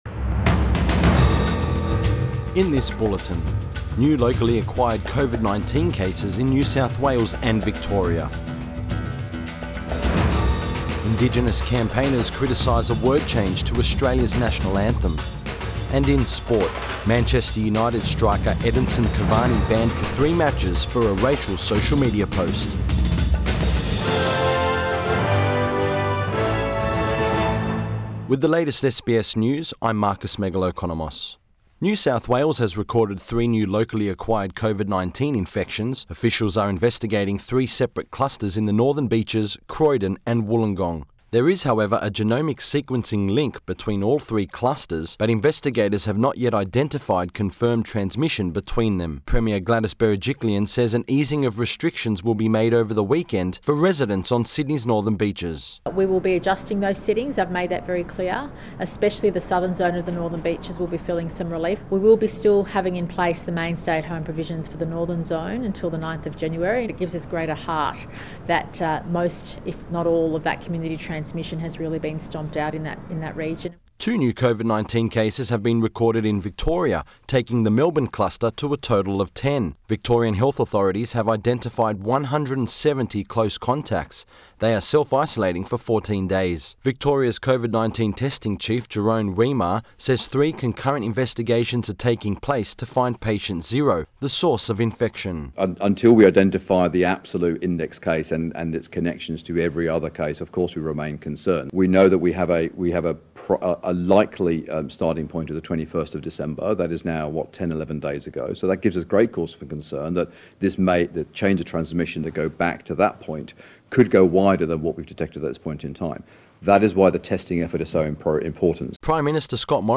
PM Bulletin January 1 2021